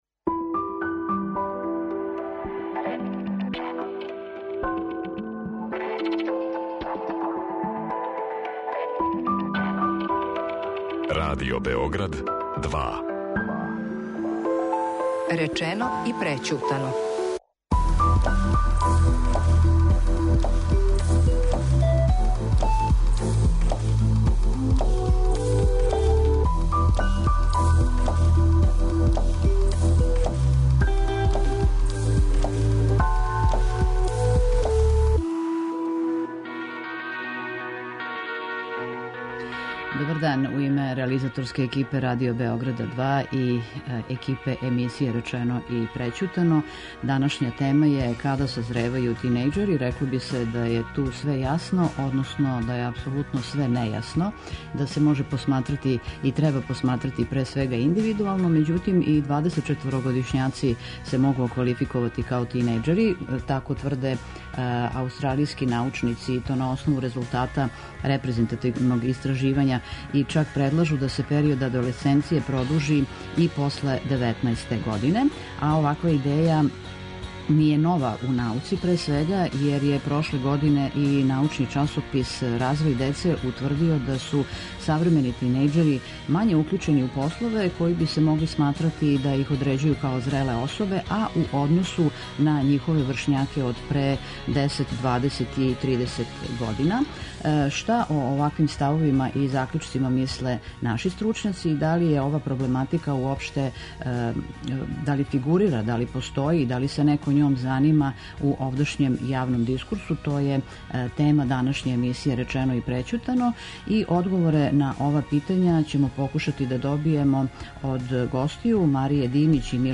Саговорници ће бити психолози